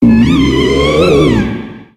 Audio / SE / Cries / GOURGEIST.ogg